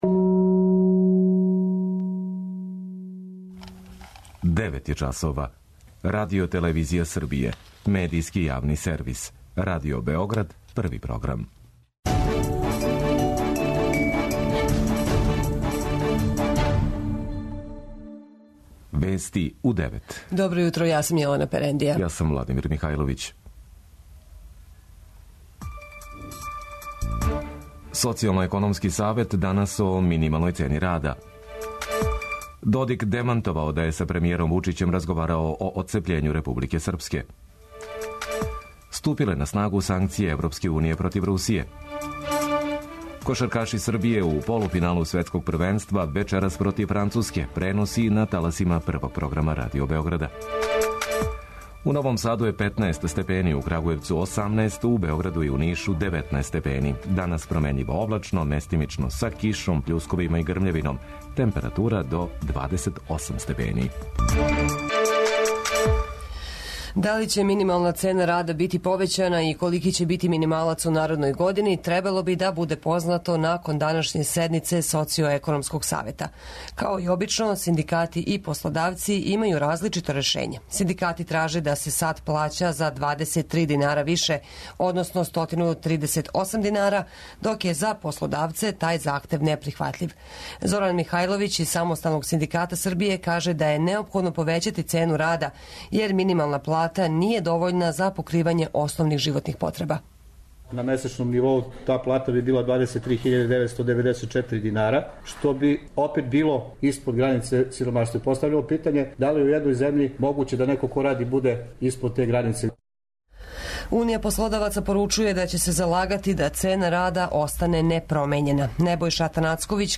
преузми : 9.66 MB Вести у 9 Autor: разни аутори Преглед најважнијиx информација из земље из света.